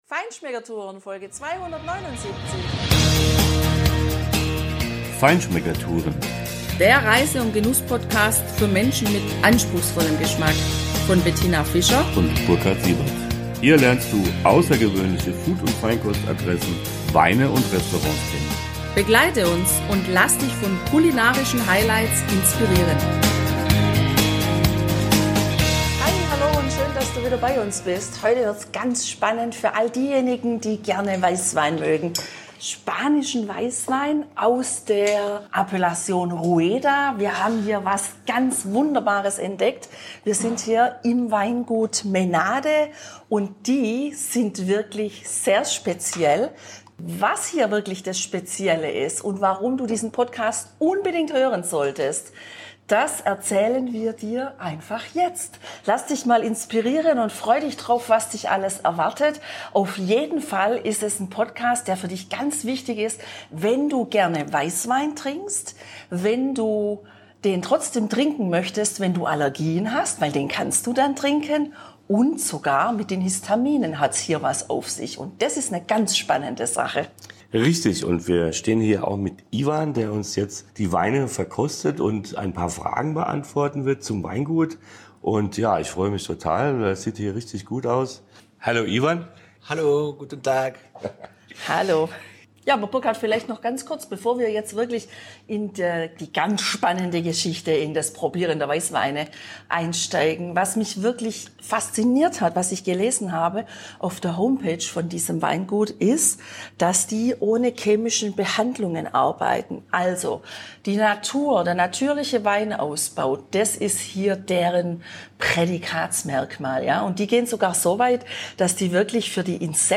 Wie immer übersetzen wir die Antworten auf Deutsch.